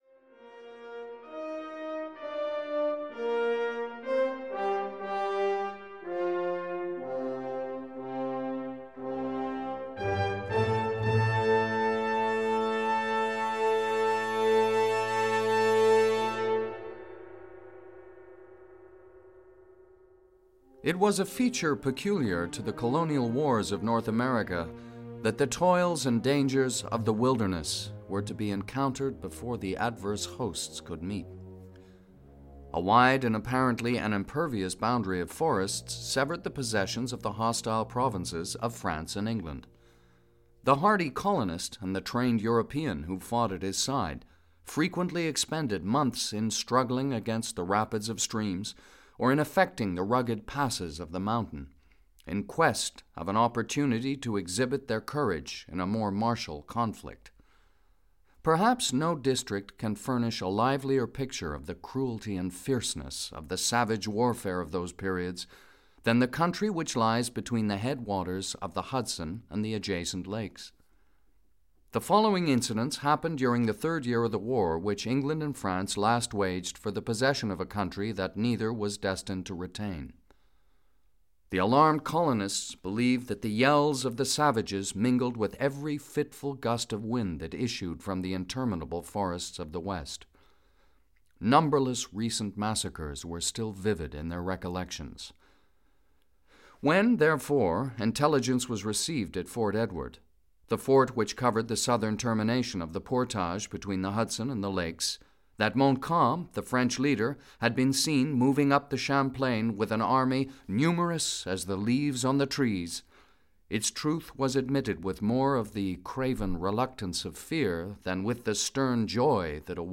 Audio kniha
Ukázka z knihy